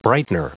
Prononciation du mot brightener en anglais (fichier audio)
Prononciation du mot : brightener